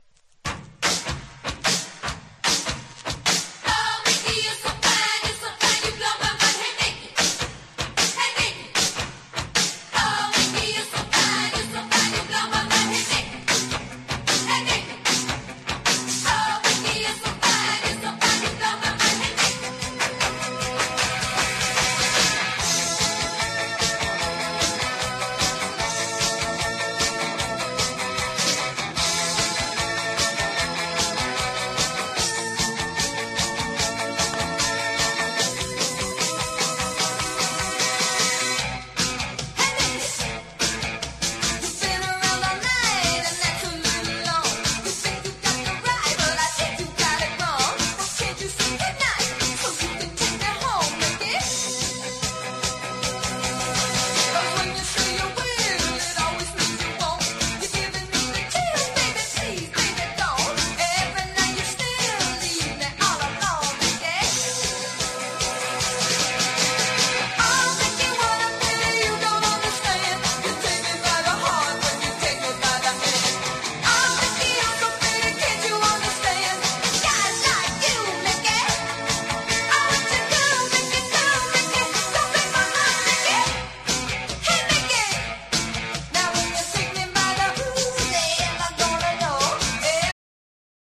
誰もが一度は耳にした事がある元気ソング！